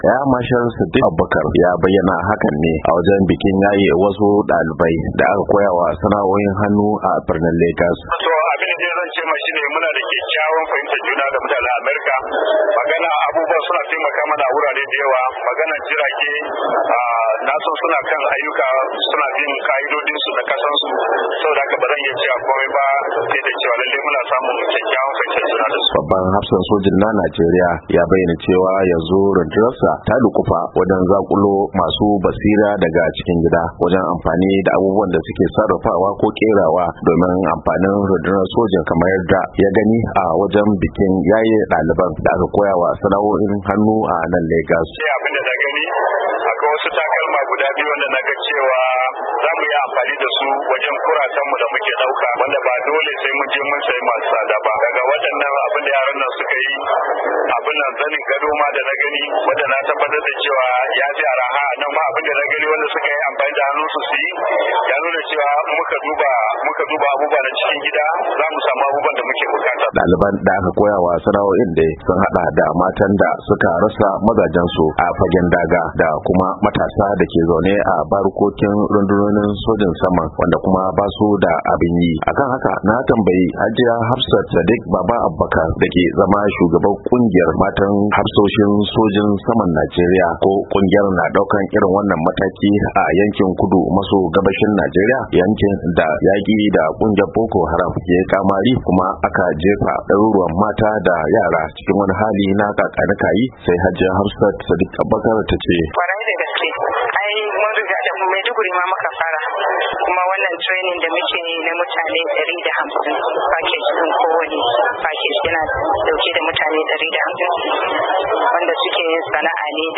Babban hafsan sojin saman Air Marshal Sadiq Abubakar, ya bayyana hakan ne a wajen bikin yaye wasu ‘dalibai da aka koyawa sana’o’in hannu a birnin Legas, wanda kuma ya ce suna da kyakkyawar fahimtar juna da Amurka, kuma suna taimaka musu a wurare da dama.